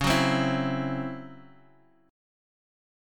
DbmM7bb5 chord